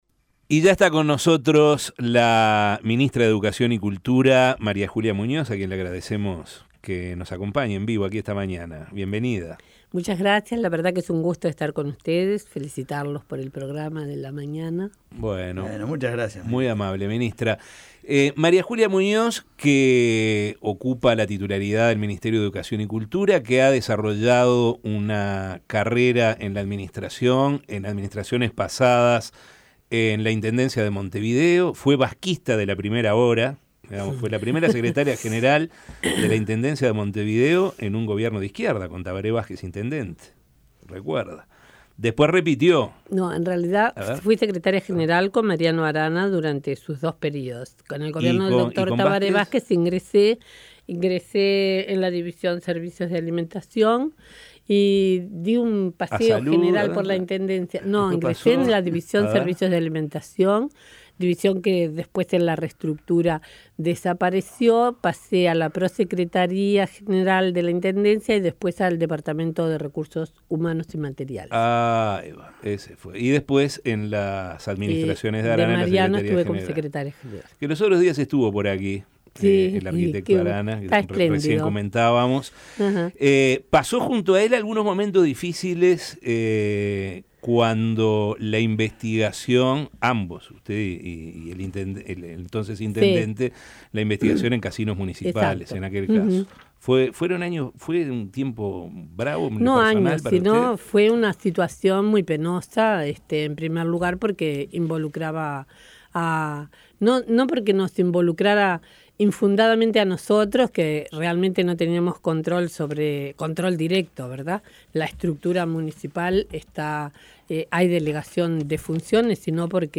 Entrevista en La Mañana